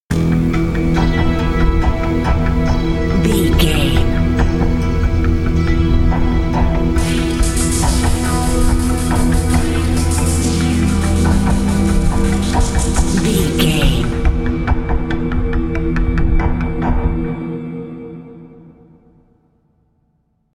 Aeolian/Minor
synthesiser